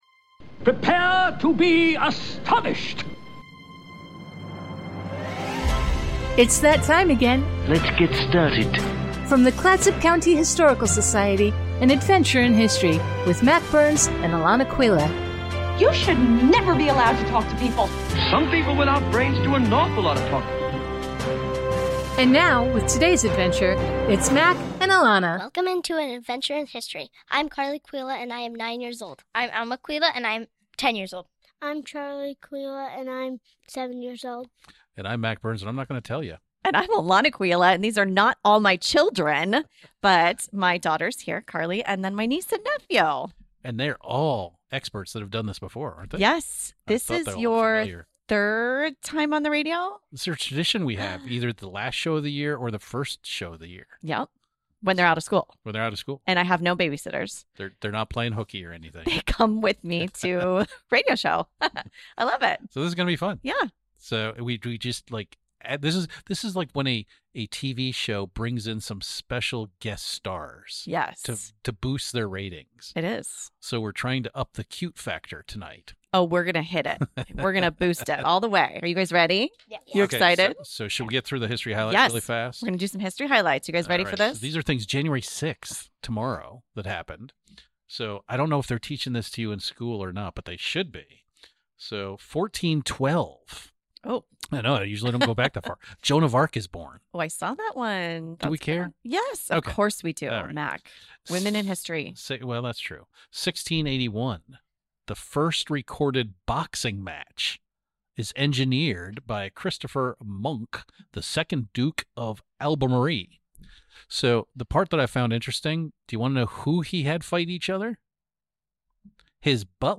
tries to boost our ratings by bringing in some cute little kids to let you know all about wacky celebrations. Mac Burns Day might be mentioned.